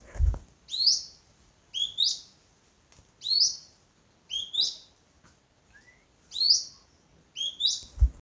Ochre-cheeked Spinetail (Synallaxis scutata)
Location or protected area: Parque Nacional Calilegua
Condition: Wild
Certainty: Observed, Recorded vocal